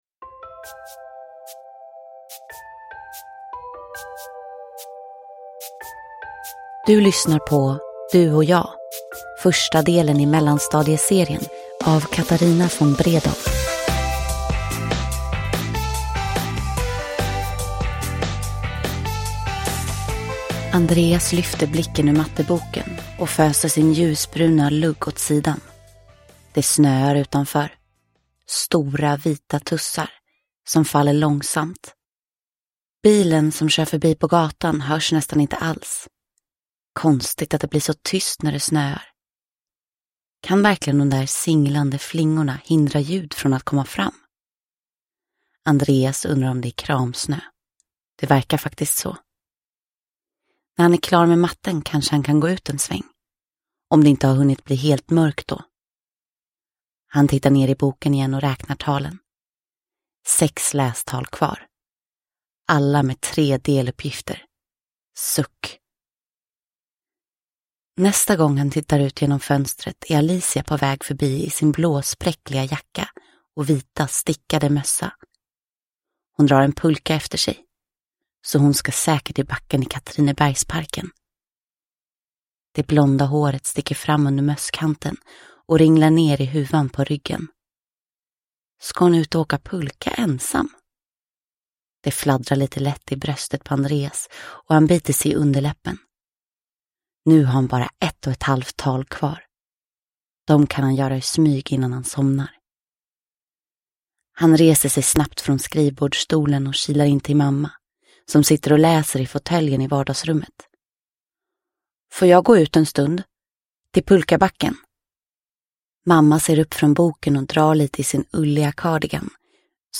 Du & jag – Ljudbok